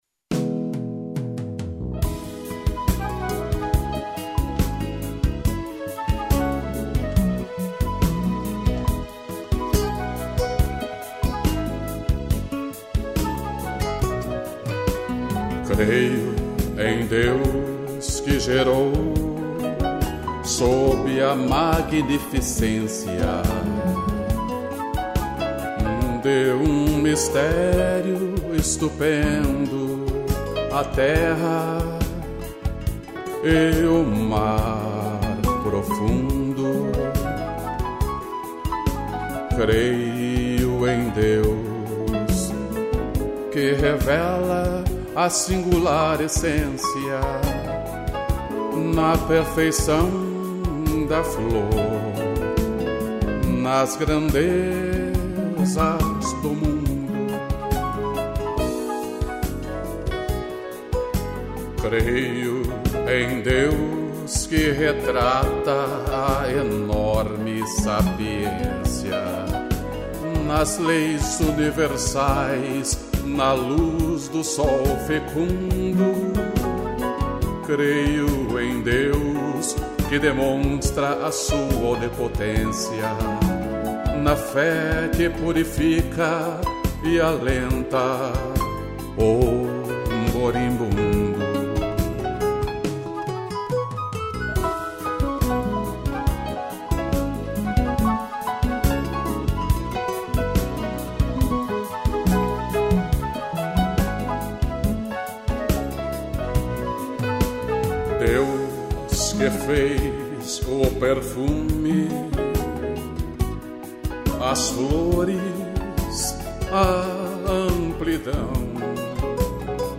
voz e violão
piano e flauta